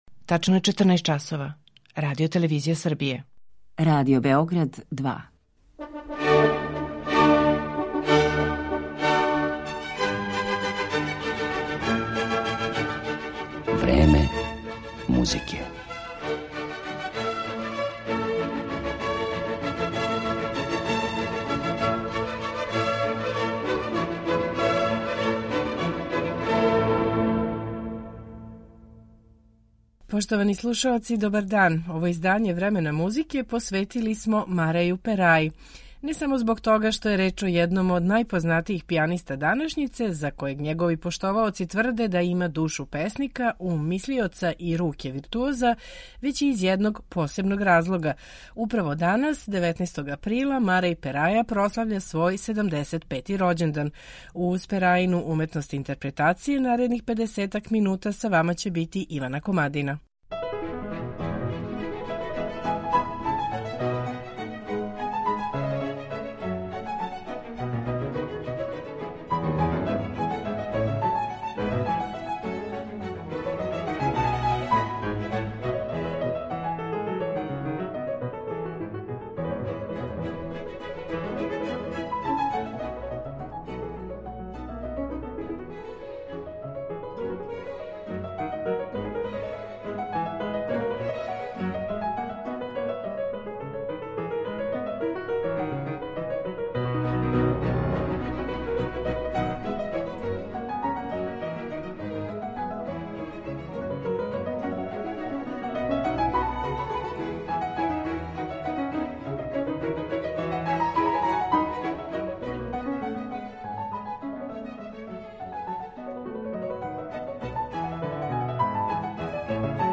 једног од најпознатијих пијаниста данашњице